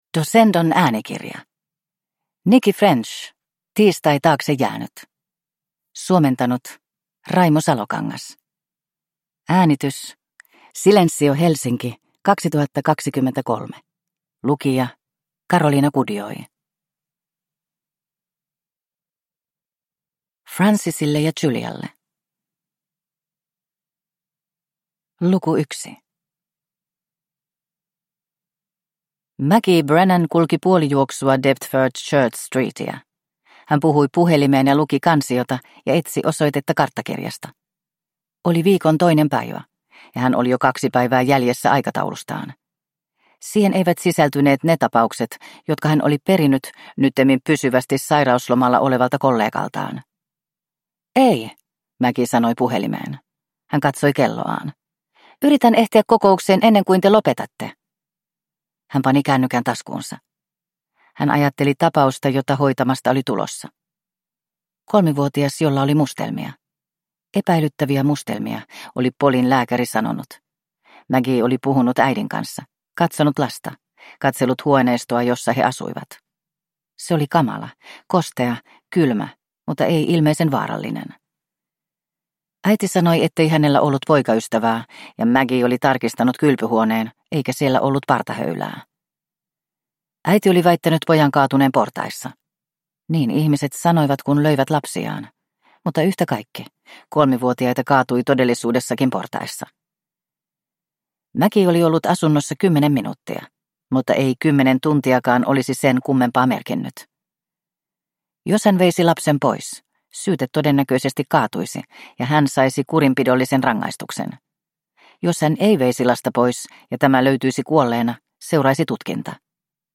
Tiistai taakse jäänyt (ljudbok) av Nicci French